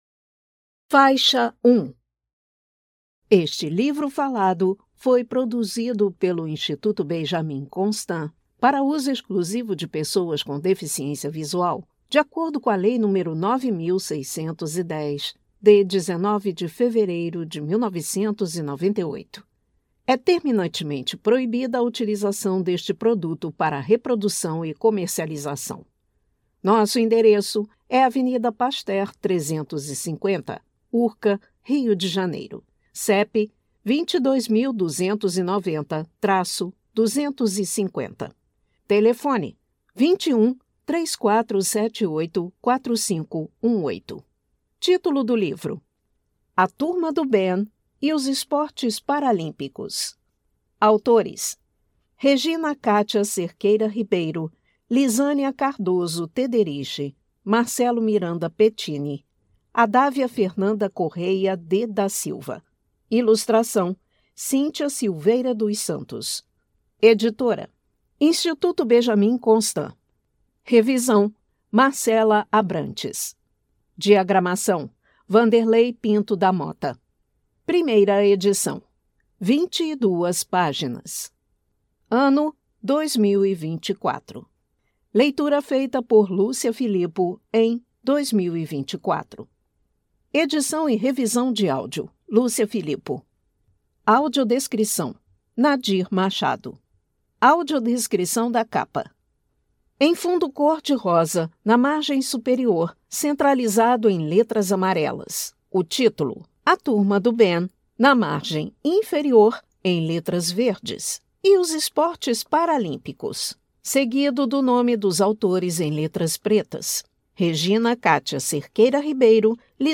Audiodescrição da capa do quadrinho A Turma do Ben e os Esportes Paralímpicos — IBC